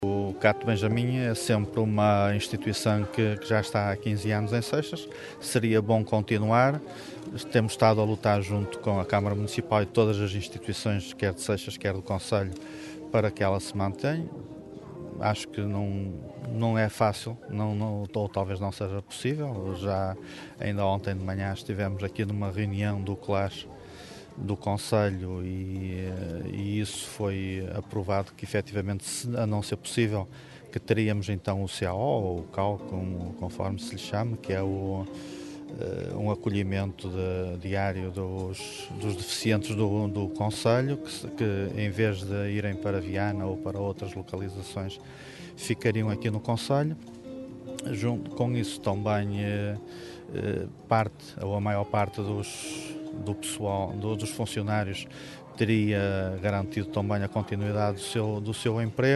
Cerca de meia centena de pessoas reuniram-se ontem ao final da tarde em Caminha para uma vigília a favor da manutenção do Centro de Acolhimento Temporário Benjamim (CAT) de Seixas, cujo encerramento por parte da APPACDM, está previsto para finais de junho.
Rui Ramalhosa, presidente da Junta de Freguesia de Seixas, começou por recordar que o CAT Benjamim é uma instituição que já está em Seixas há 15 anos e por isso seria bom poder continuar, embora reconheça que não será fácil.